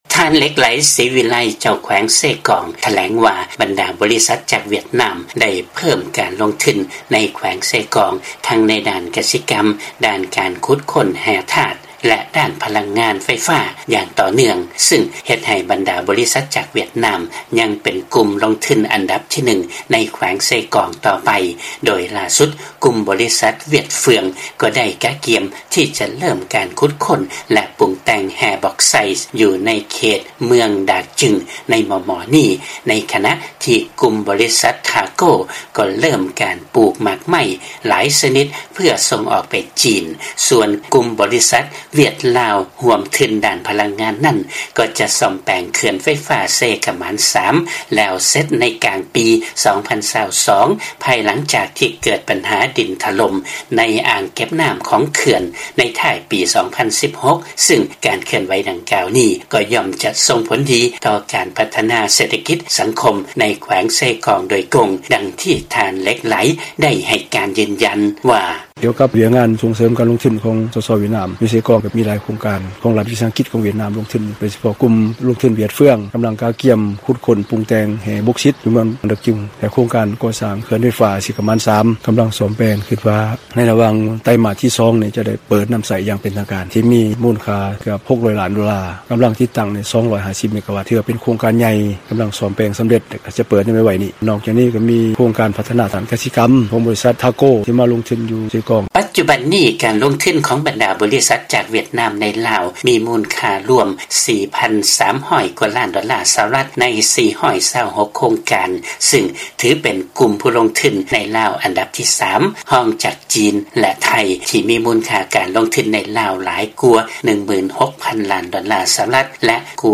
ຟັງລາຍງານ ບັນດາບໍລິສັດຈາກຫວຽດນາມ ເພີ່ມການລົງທຶນໃນແຂວງເຊກອງ ແລະ ການສ້ອມແປງເຂື່ອນເຊຂະໝານ-3 ຈະສຳເລັດໃນກາງປີ 2022 ນີ້